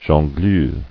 [jon·gleur]